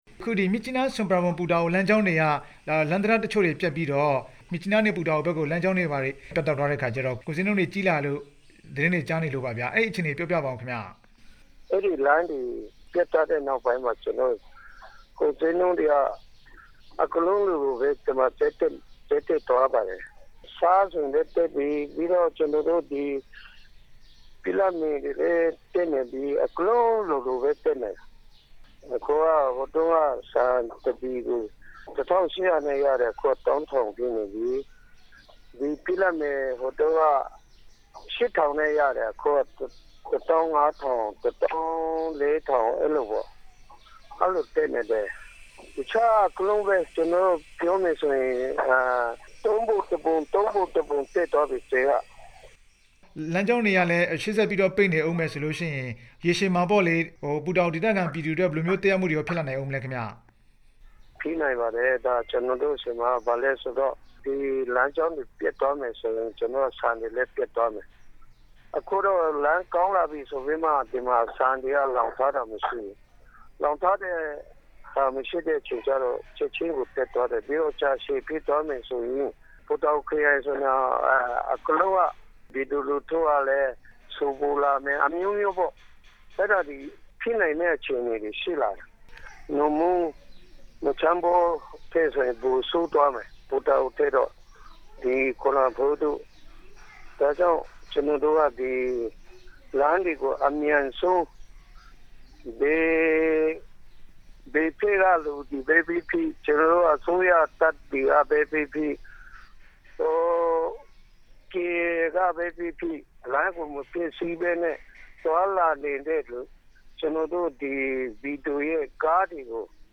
ဒီကနေ့ ဆက်သွယ်မေးမြန်းထားပါတယ်။